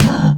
Minecraft Version Minecraft Version snapshot Latest Release | Latest Snapshot snapshot / assets / minecraft / sounds / mob / camel / hurt4.ogg Compare With Compare With Latest Release | Latest Snapshot
hurt4.ogg